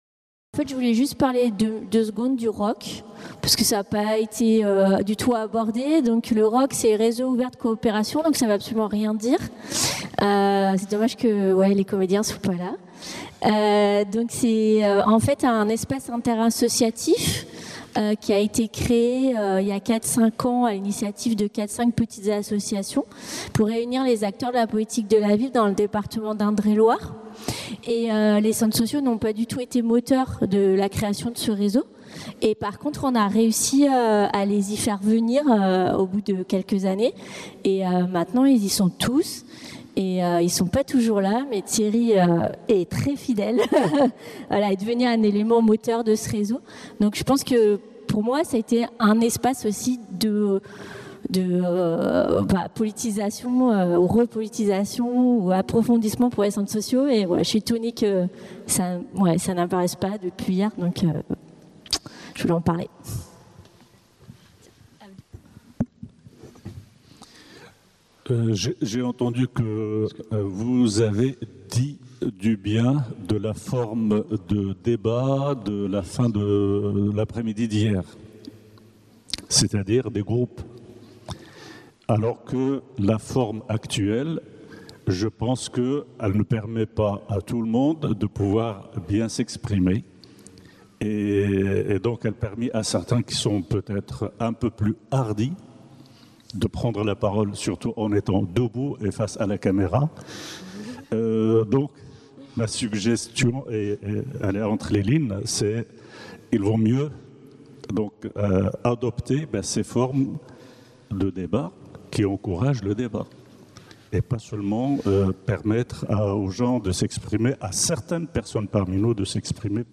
15- (Table ronde 3) : Débat avec le public (4) | Canal U